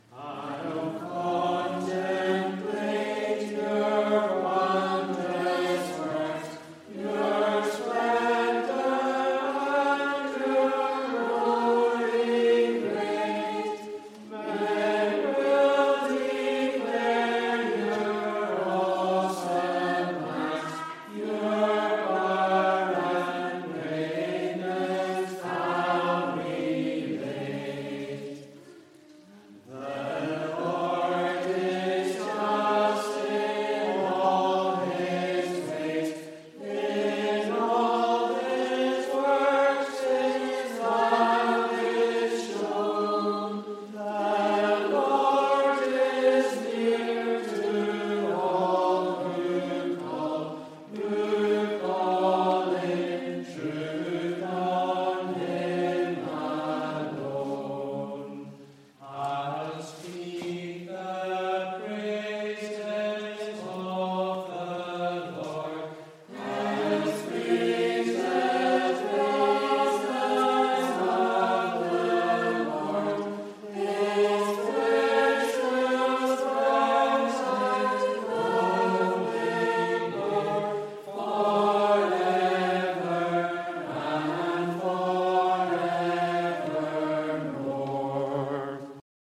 Psalm 145 (Eisenach) Morning Service 28 April 2024
Congregational Psalm Singing